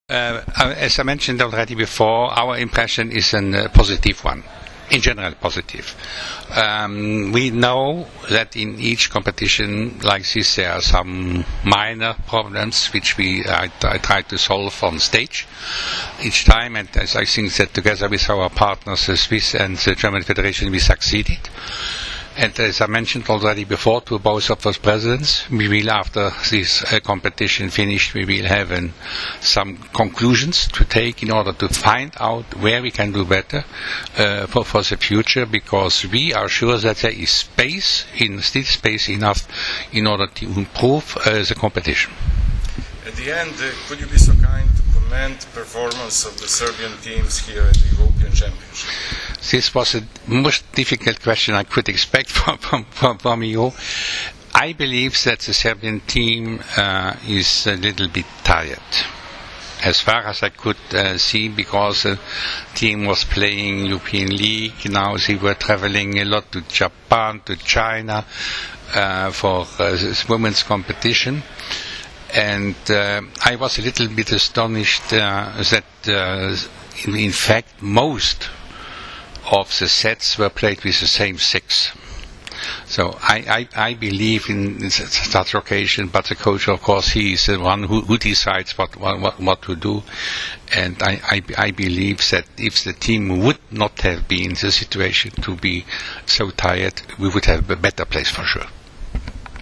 Po završetku utakmice za treće mesto na EP između Srbije i Belgije, u pres sali dvorane “Maks Šmeling” u Berlinu održana je konferencija za novinare, na kojoj su se predstavnicima medija obratili Andre Mejer iz Luksemburga, predsednik CEV, Tomas Krone, predsednik Odbojkaške federacije Nemačke i Kristof Štern, predsednik Odbojkaške federacije Švajcarske.
IZJAVA ANDREA MEJERA